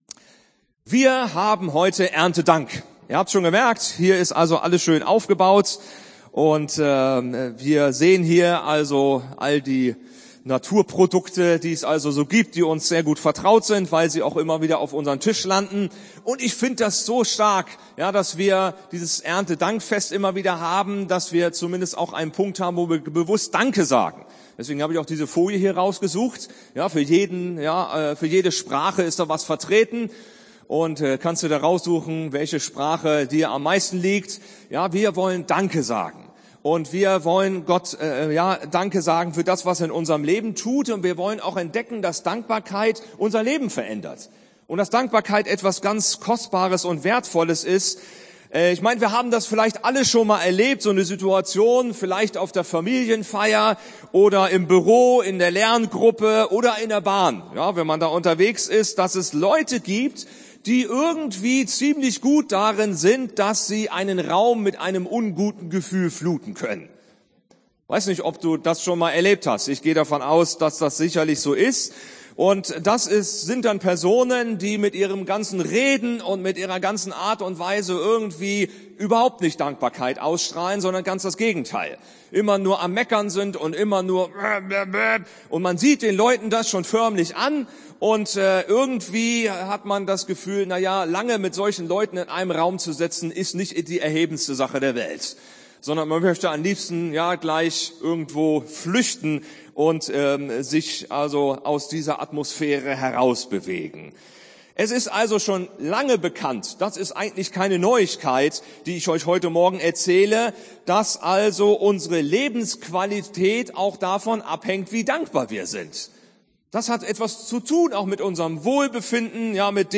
Predigten 2020